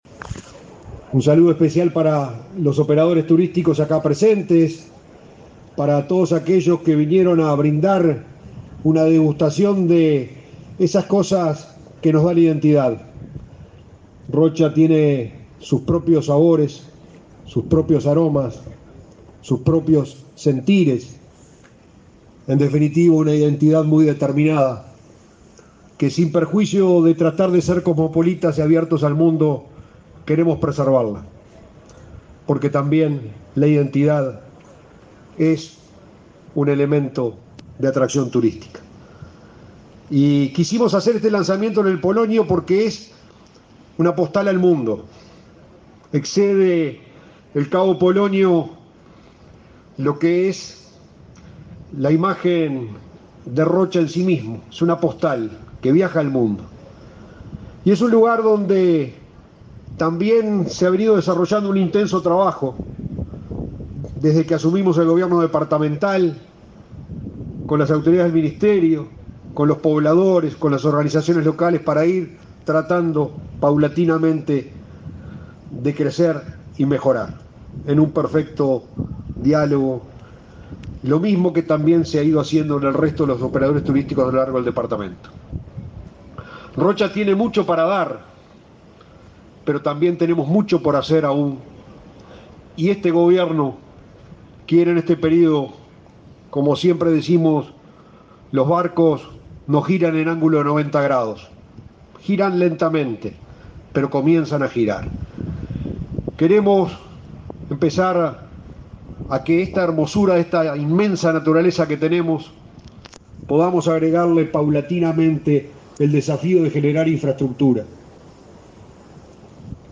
Lanzamiento de temporada turística de Rocha 16/12/2021 Compartir Facebook X Copiar enlace WhatsApp LinkedIn El intendente de Rocha, Alejo Umpiérrez; el ministro de Ambiente, Adrián Peña, y su par de Turismo, Tabaré Viera, participaron del lanzamiento de la temporada turística de Rocha, este jueves 16 en Cabo Polonio.